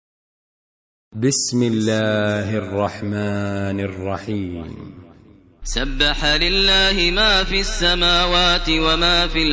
Audio Quran Tarteel Recitation
Surah Repeating تكرار السورة Download Surah حمّل السورة Reciting Murattalah Audio for 61. Surah As-Saff سورة الصف N.B *Surah Includes Al-Basmalah Reciters Sequents تتابع التلاوات Reciters Repeats تكرار التلاوات